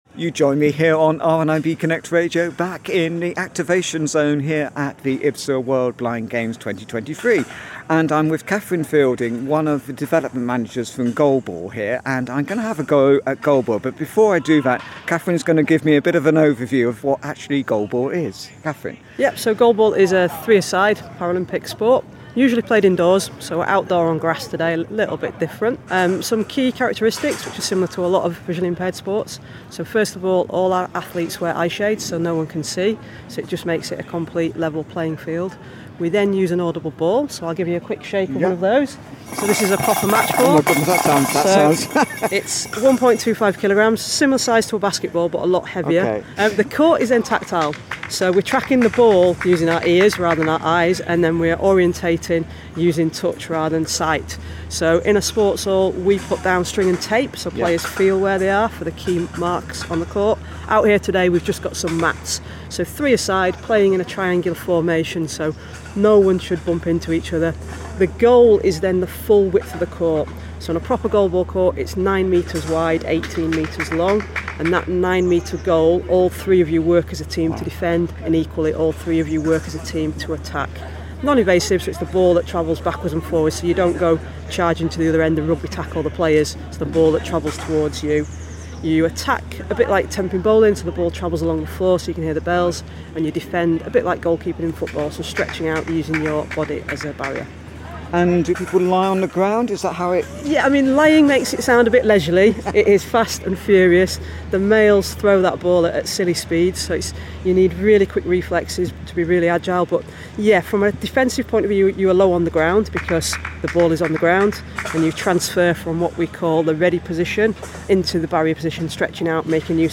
IBSA World Blind Games 2023, Activation Zone - Goalball Lesson